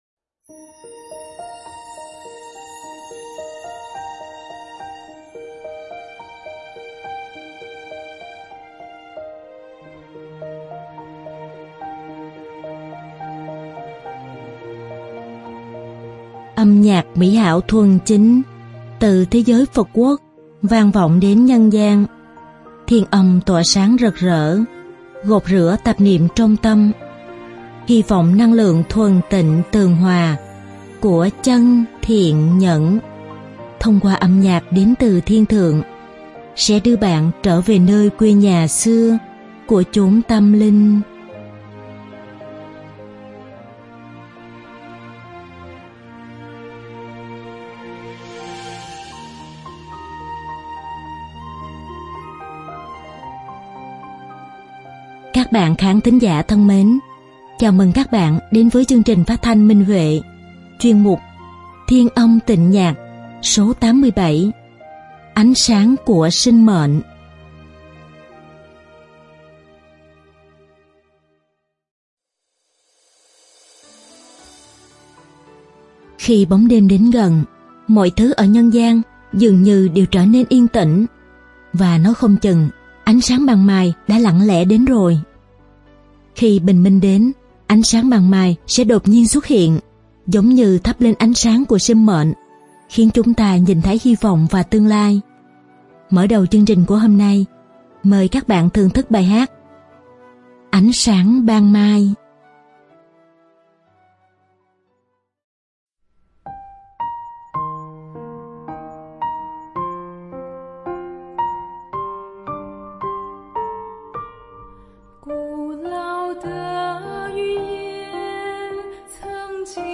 Đơn ca nam